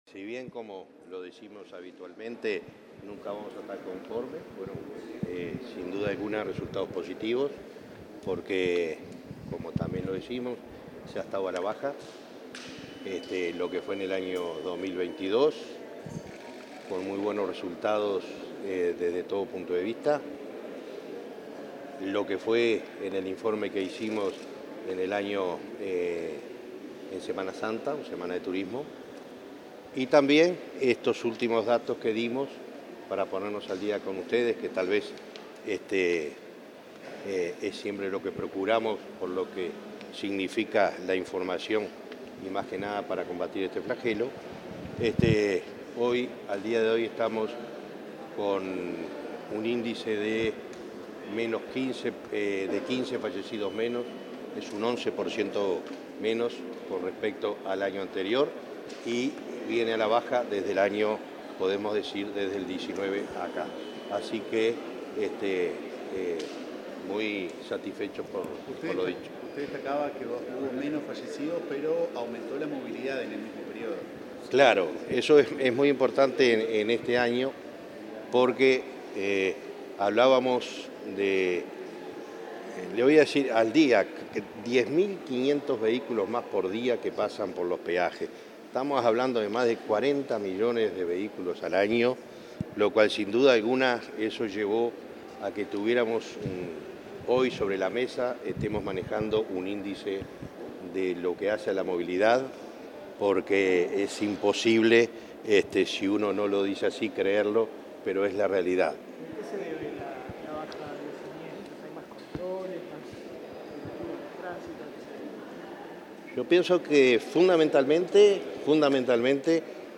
Declaraciones del presidente de Unasev, Alejandro Draper
Declaraciones del presidente de Unasev, Alejandro Draper 21/04/2023 Compartir Facebook X Copiar enlace WhatsApp LinkedIn El presidente de la Unidad Nacional de Seguridad Vial (Unasev), Alejandro Draper, presentó este viernes 21 en la Torre Ejecutiva los datos de siniestralidad de 2022. Luego dialogó con la prensa.